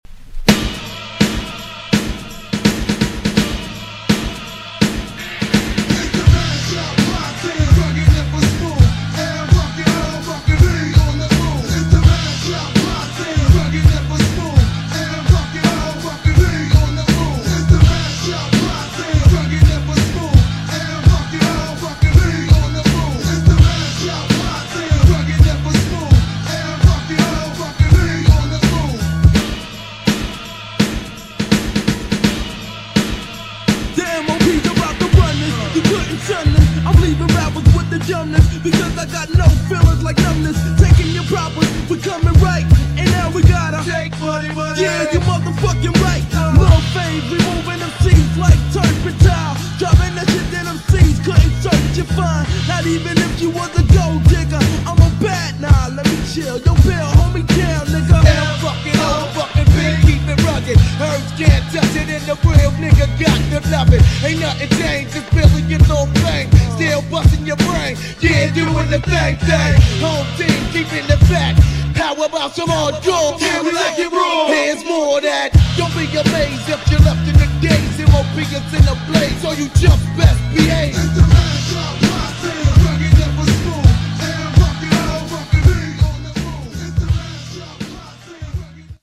GENRE Hip Hop
BPM 81〜85BPM